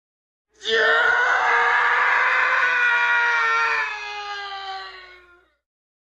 This funny :) (Man Dinosaur sound effects free download
This funny :) (Man Dinosaur Scream sound effect)